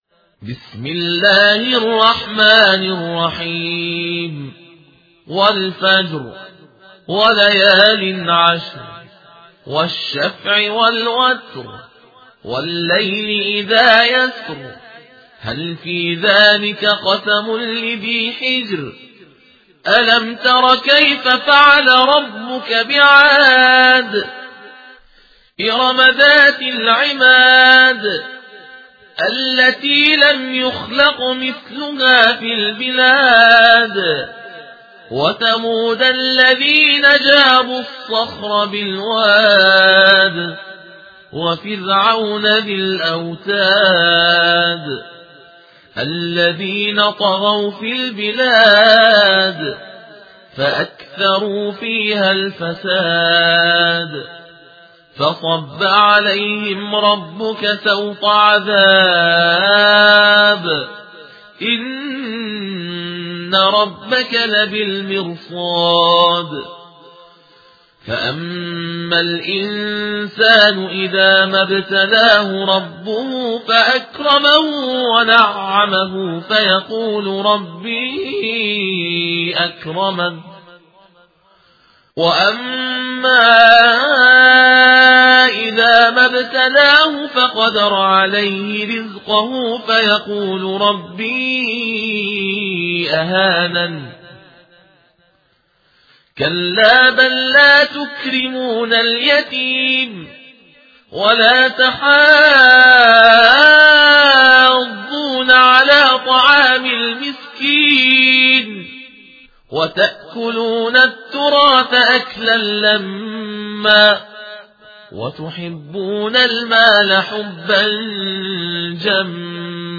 تلاوت ترتیل